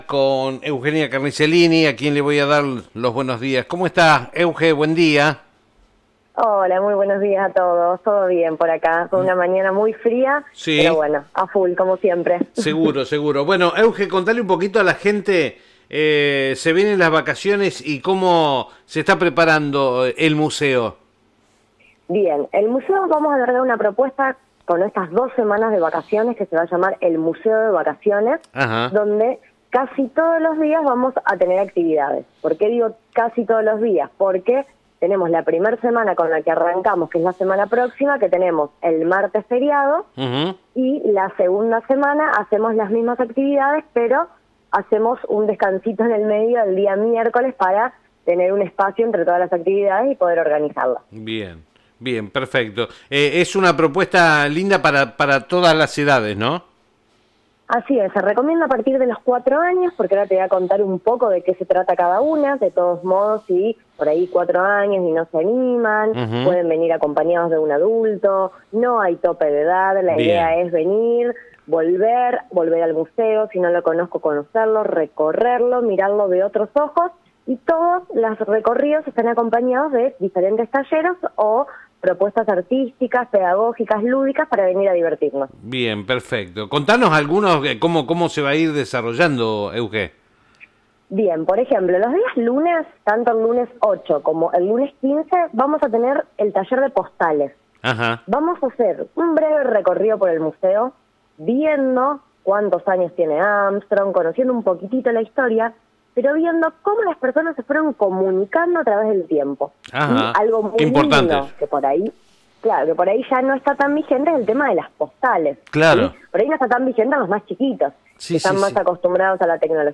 Comunicación Telefónica en FM Sol 91.7